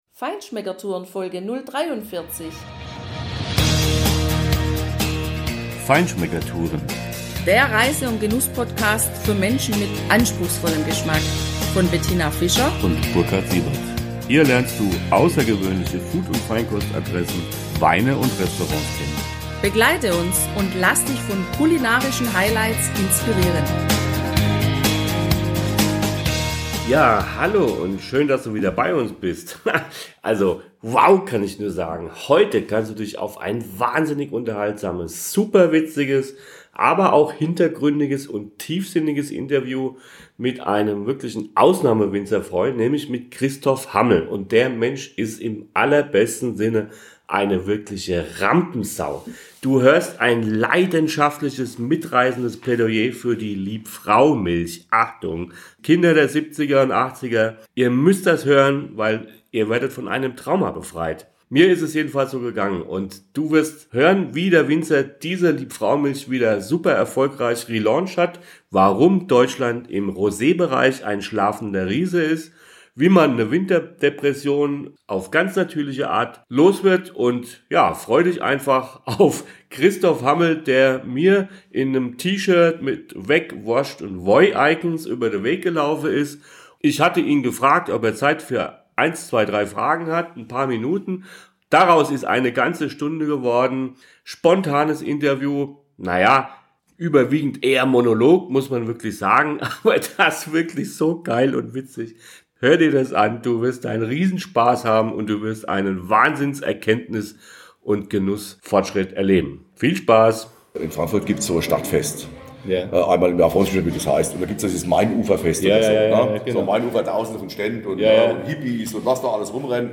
Wow! Heute kannst du dich auf ein wahnsinnig unterhaltsames, super witziges, aber auch hintergründiges und tiefsinniges Interview mit einem Winzer freuen,